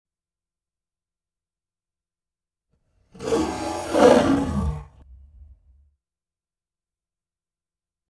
Lion Roar In The Wild Bouton sonore